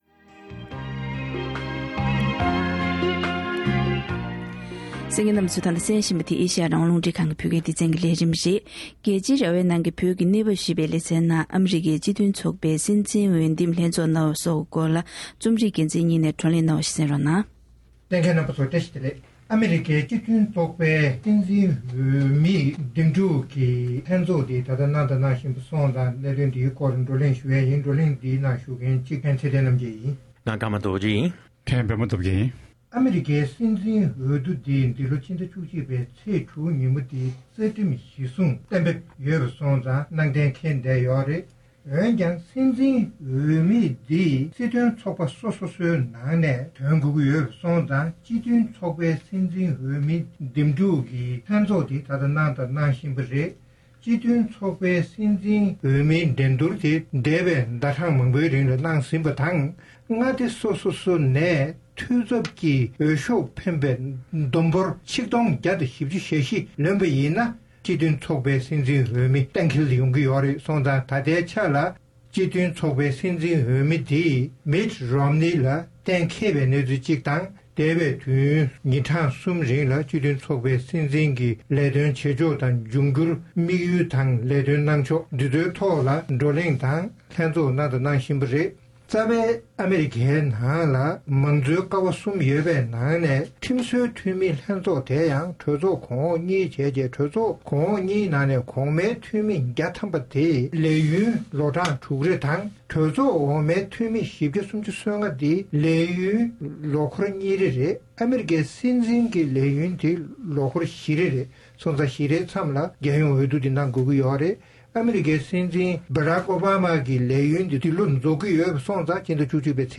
ཨ་མི་རི་ཀའི་སྤྱི་མཐུན་ཚོགས་པའི་སྲིད་འཛིན་འོས་འདེམས་ལྷན་ཚོགས་གནང་བ་སོགས་ཀྱི་སྐོར་བགྲོ་གླེང་།